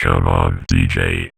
VVE1 Vocoder Phrases 08.wav